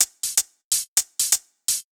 Index of /musicradar/ultimate-hihat-samples/125bpm
UHH_ElectroHatA_125-02.wav